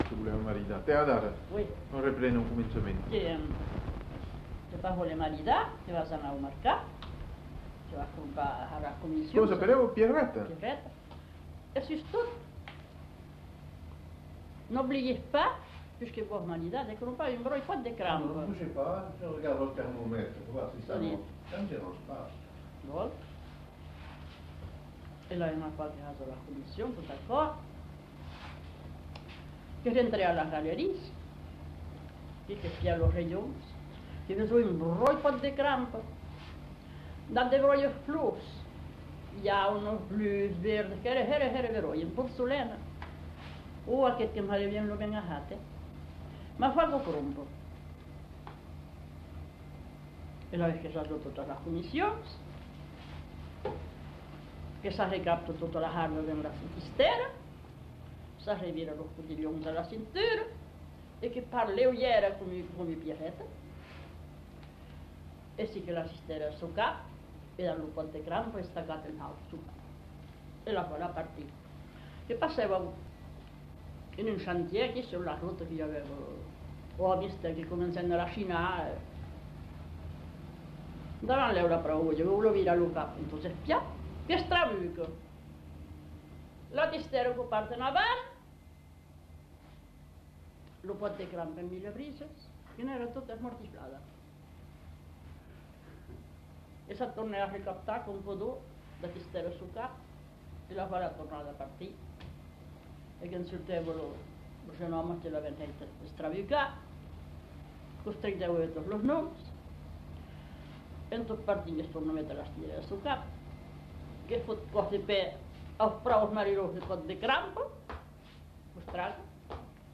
Aire culturelle : Marsan
Lieu : Landes
Genre : conte-légende-récit
Effectif : 1
Type de voix : voix de femme
Production du son : parlé